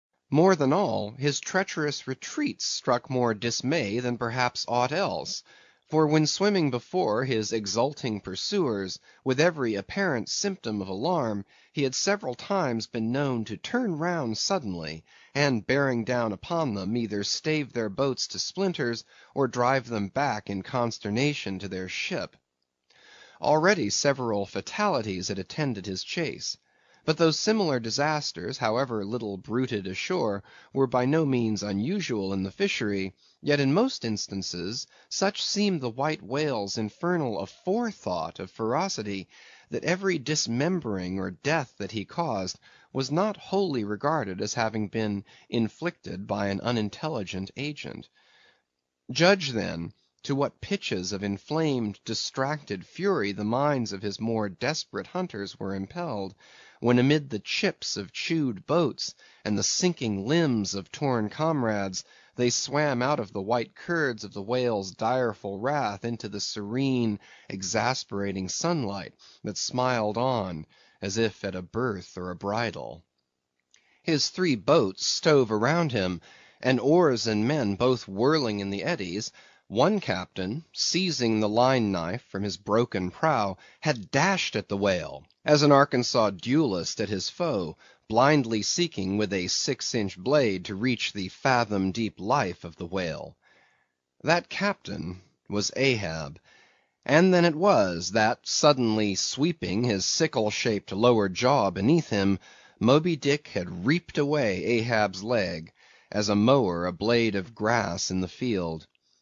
英语听书《白鲸记》第442期 听力文件下载—在线英语听力室